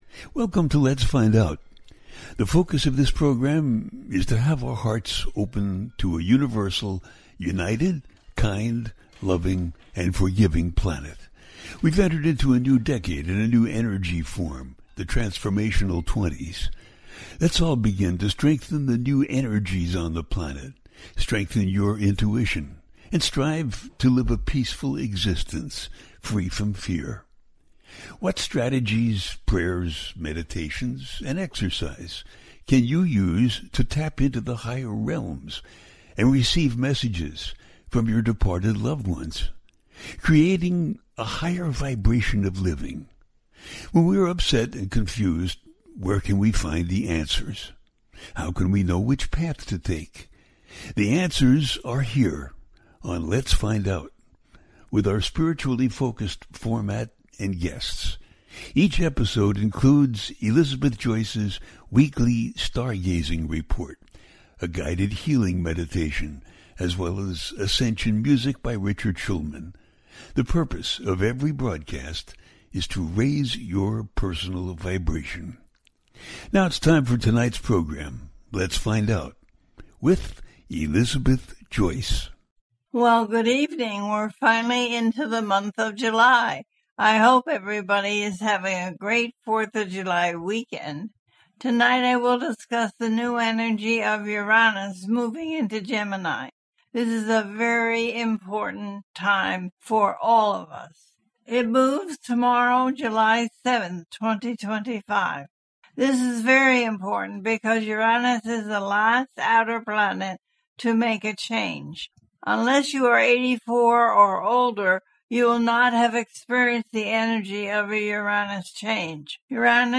In July Uranus Moves into Gemini, The Capricorn Full Moon Part 2 - A teaching show
The listener can call in to ask a question on the air.
Each show ends with a guided meditation.